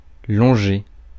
Ääntäminen
IPA: [lɔ̃.ʒe]